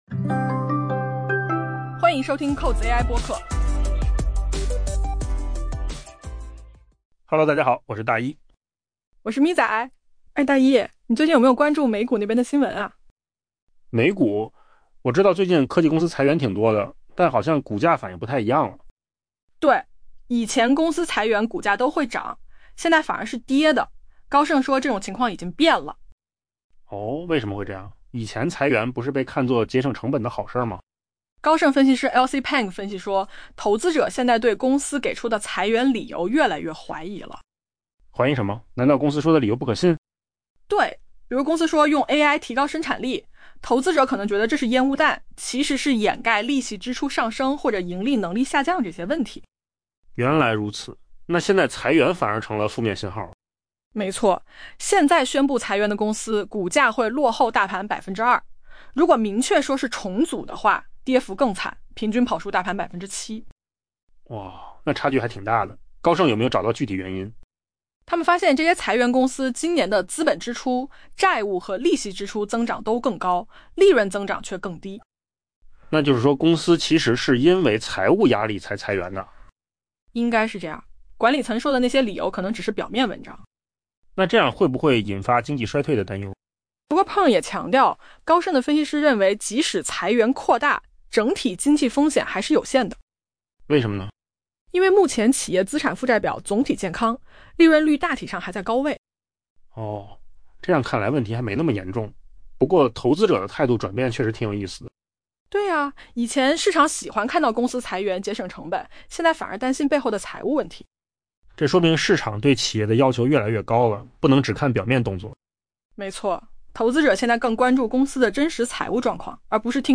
【文章来源：金十数据】AI播客：换个方
AI 播客：换个方式听新闻 下载 mp3 音频由扣子空间生成 过去， 当美股上市公司宣布裁员时，股价通常会上涨。